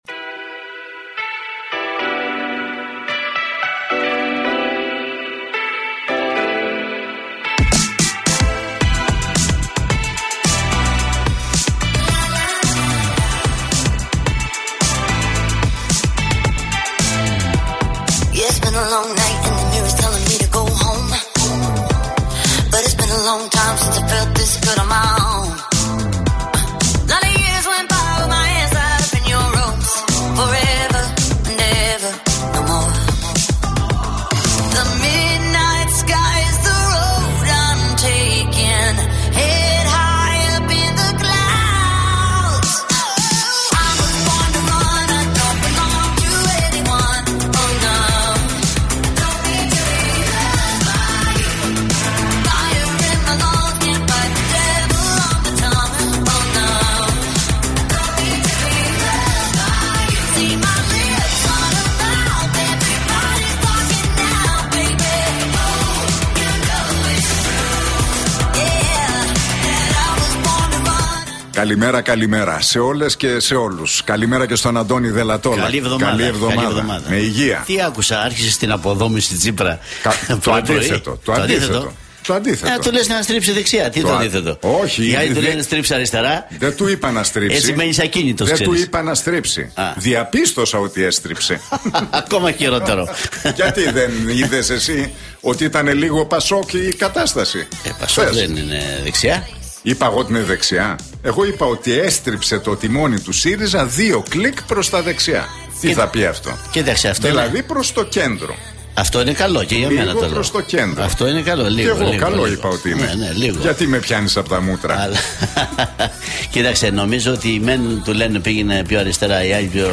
Ακούστε την εκπομπή του Νίκου Χατζηνικολάου στον Real Fm 97,8, την Δευτέρα 20 Σεπτεμβρίου 2021.